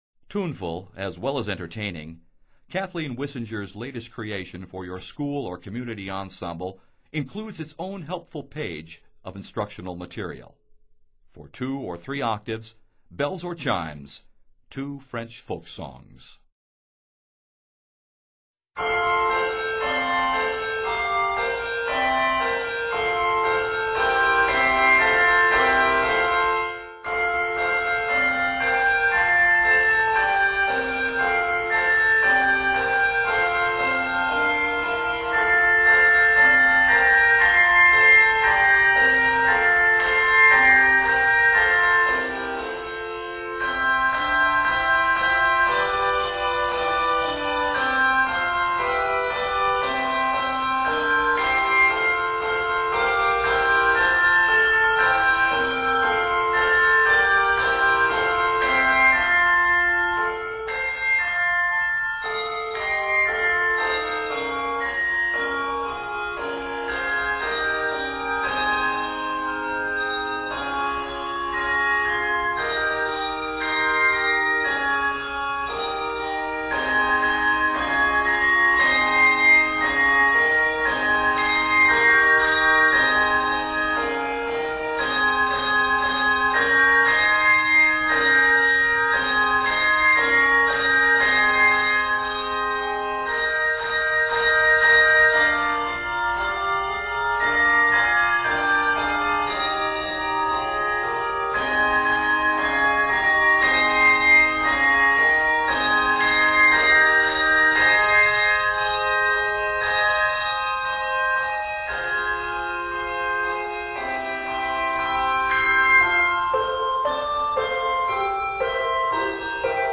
entertaining and playful work